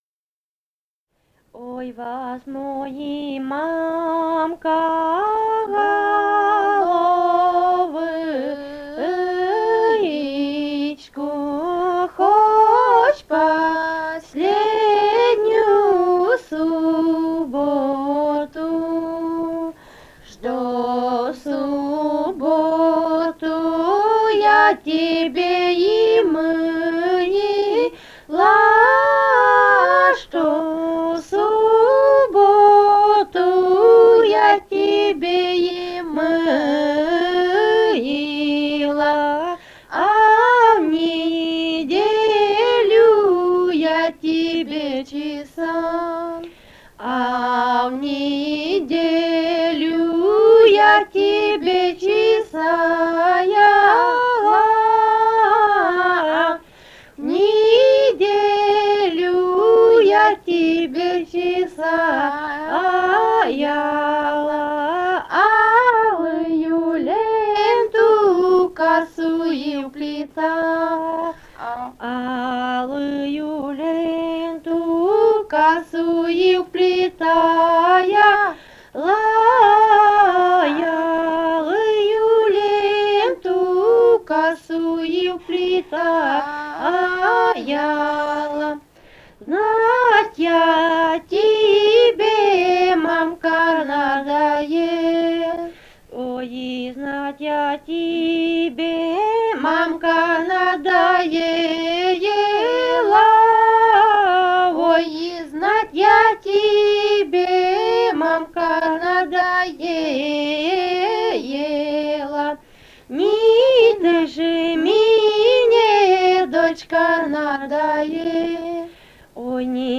Этномузыкологические исследования и полевые материалы
Румыния, с. Переправа, 1967 г. И0974-11